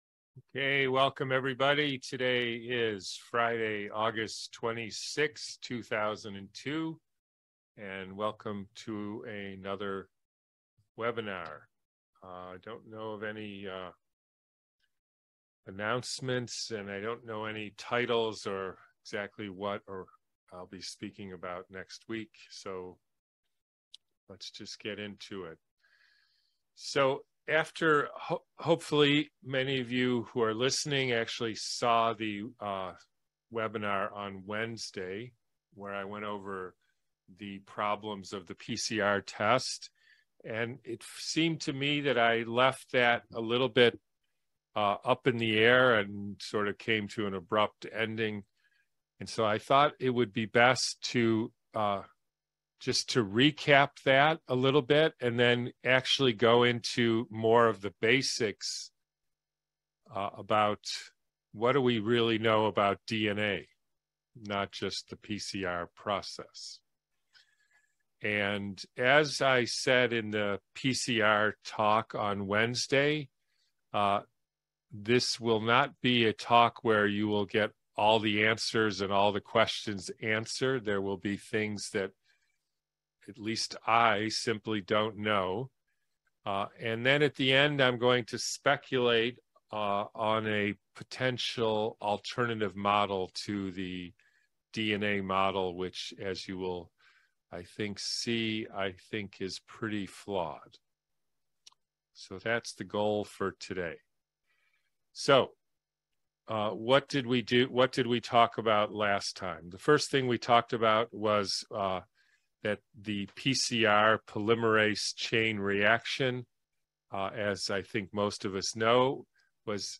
''Genetics'', DNA, and RNA- Webinar from August 26th, 2022